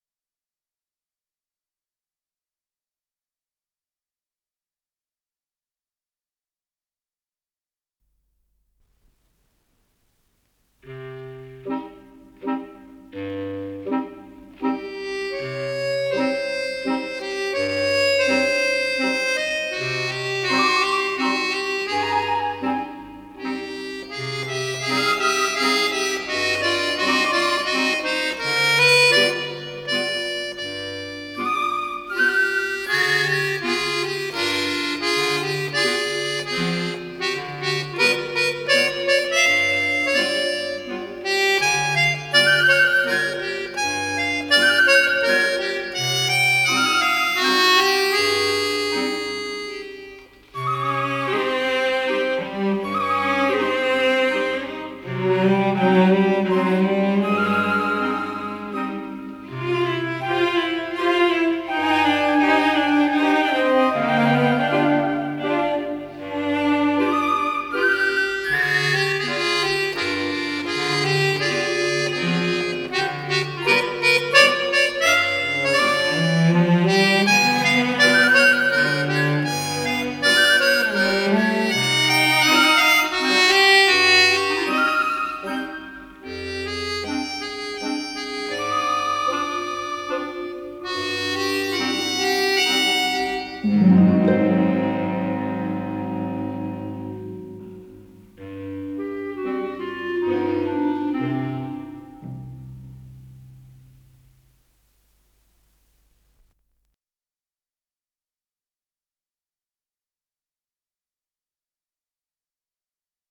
Скорость ленты38 см/с
МагнитофонМЭЗ-109М